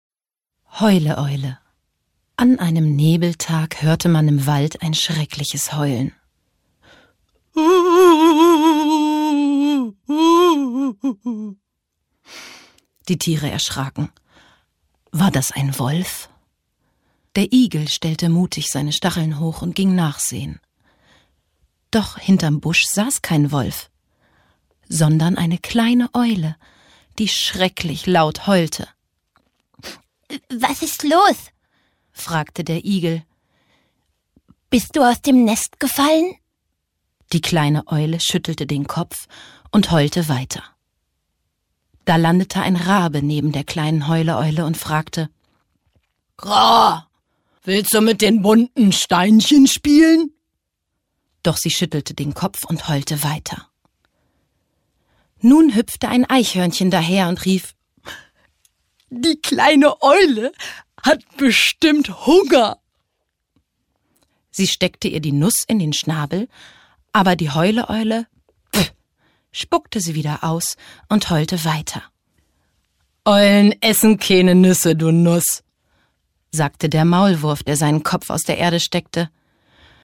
Werbung sinnlich
Werbung erotisch
Kindergeschichte